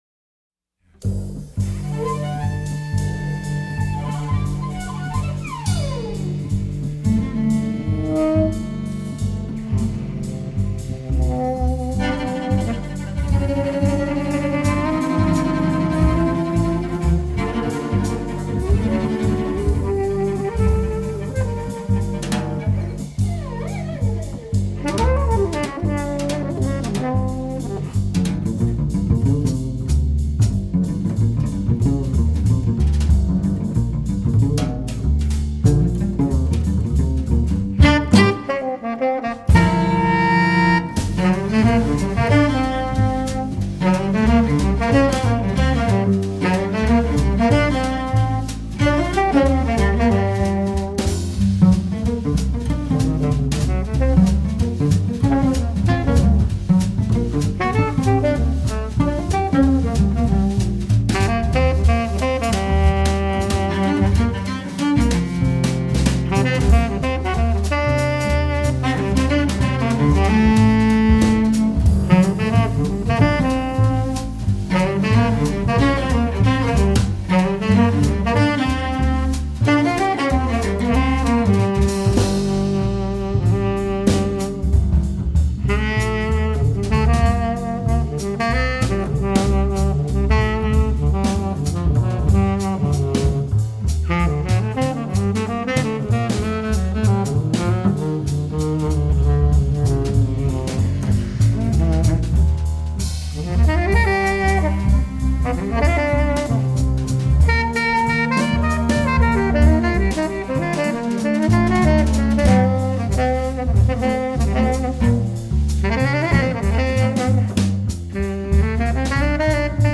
cello
saxophones
bass
drums.